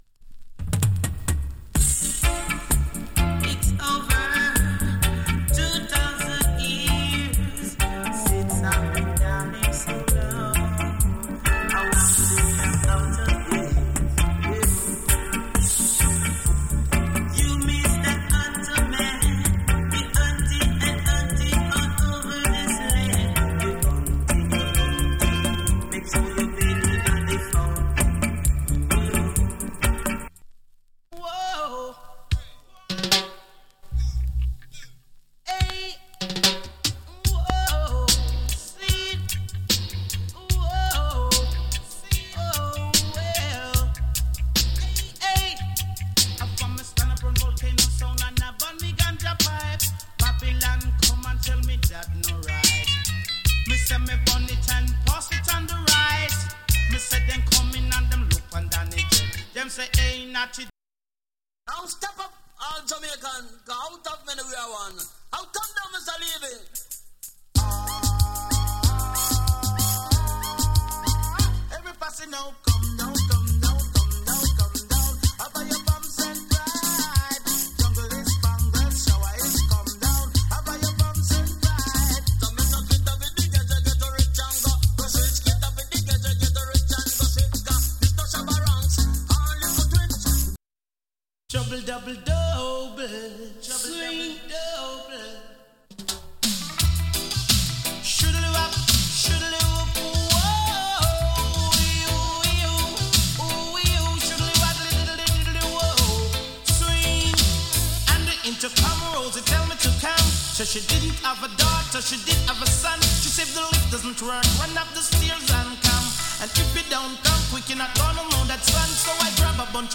チリ、パチノイズわずかに有り。